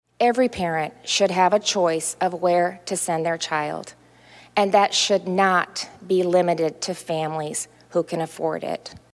Reynolds outlined the plan during the annual Condition of the State address at the statehouse.